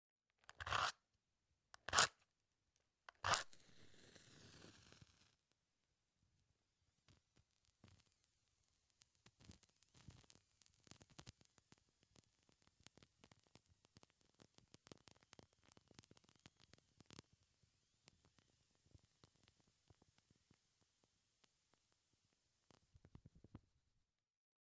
描述：在其盒子上打一个安全火柴，让它在麦克风附近烧一会儿。